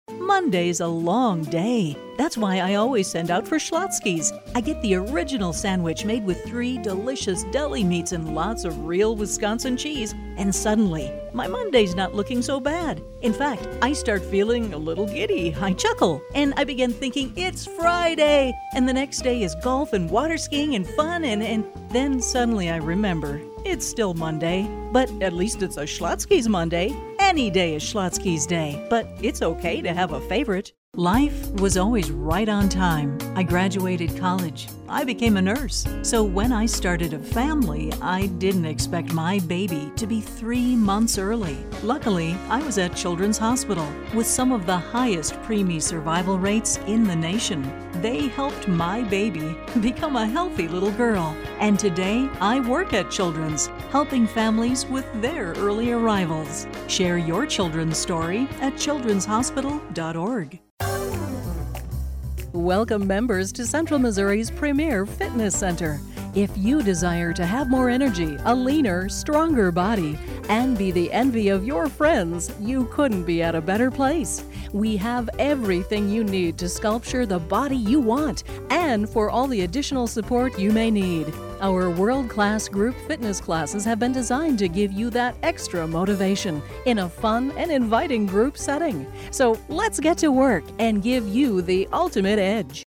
• 10USA Female No.2
Commercial